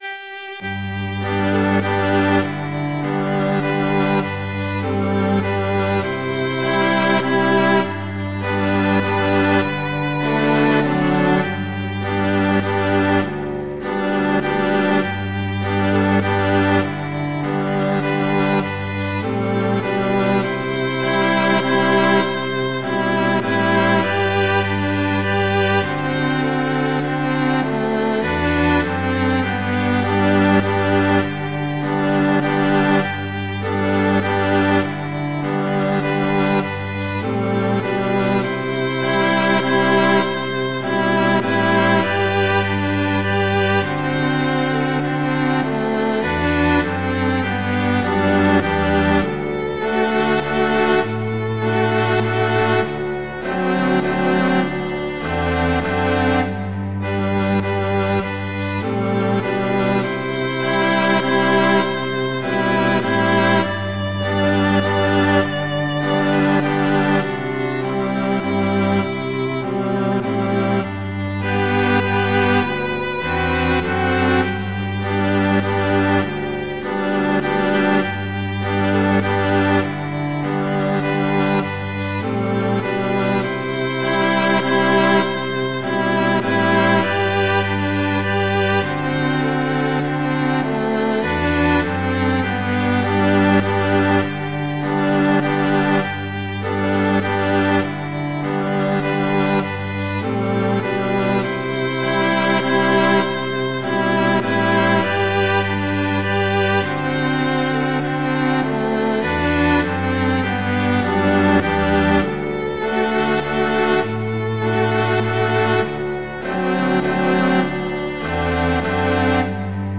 traditional, irish